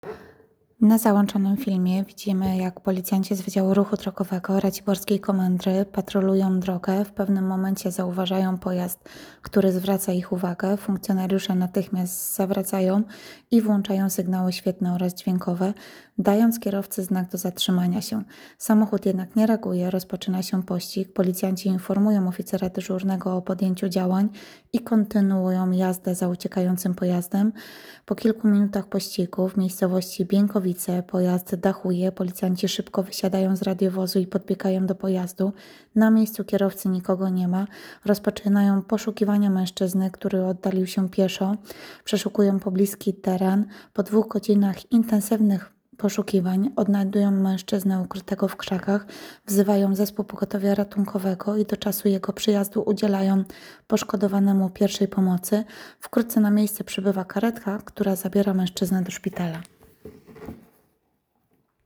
Nagranie audio Audiodyskrypcja filmu